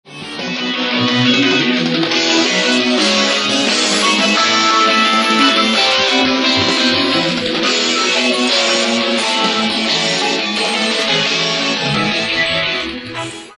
（ボカロなので苦手な方はご注意を）
殆どの上モノが割と綺麗に出てるが、上4パート以外が全部まとまるので耳コピには使いづらいかも